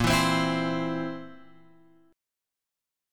A# Suspended 2nd Flat 5th